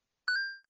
droplet_good5
8-bit good pick-up sound effect free sound royalty free Music